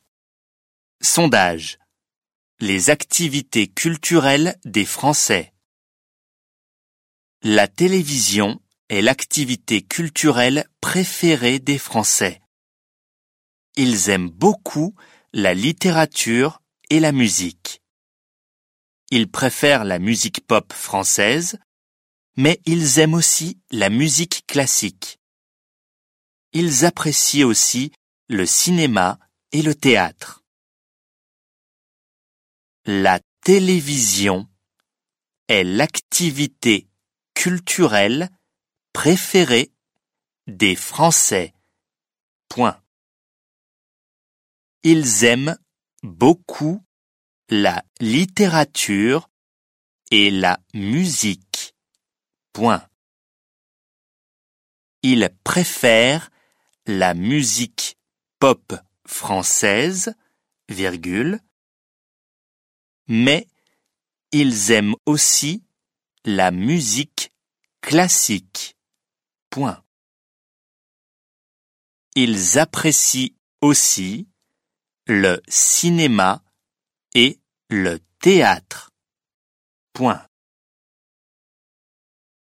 دیکته - مبتدی